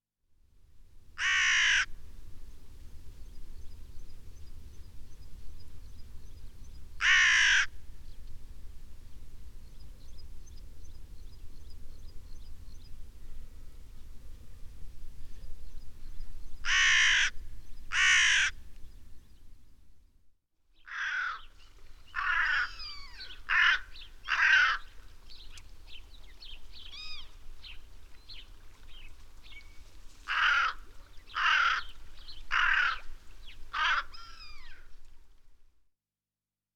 Rabenkrähe Ruf
Rabenkraehe-Voegel-in-Europa.wav